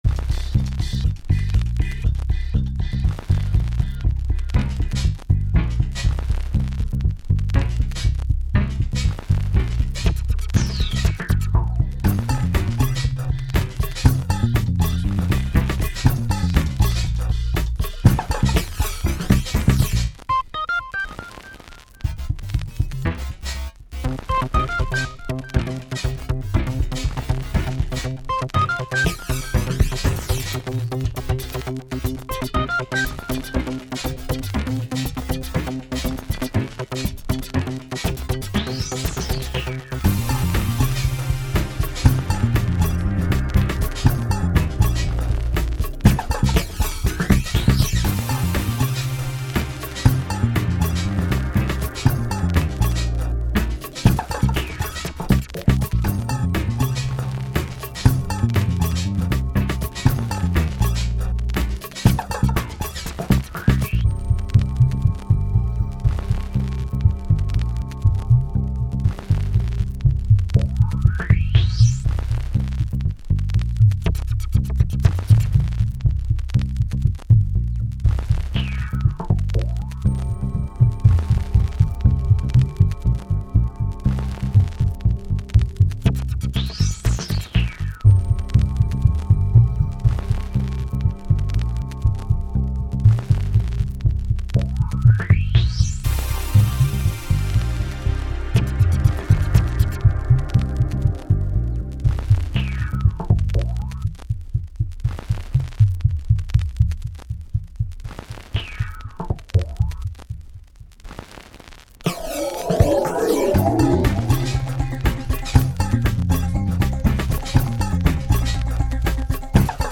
dance/electronic